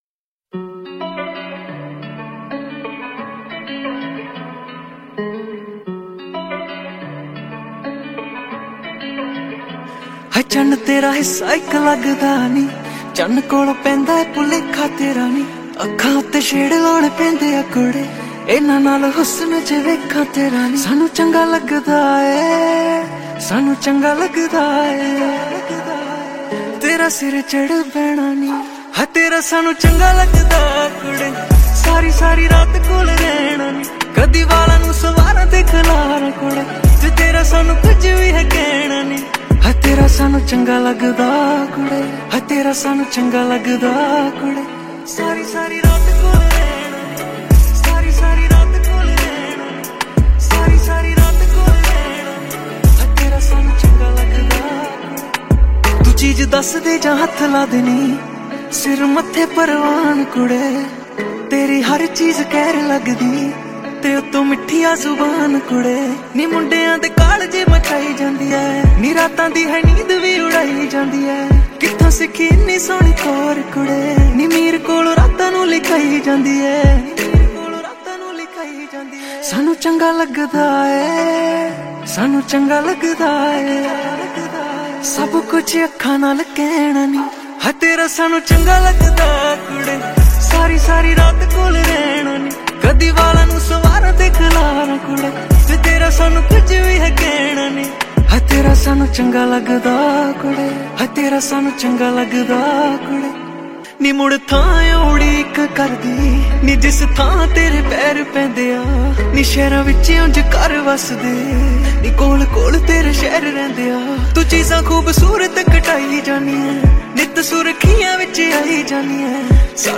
New Punjabi Song 2023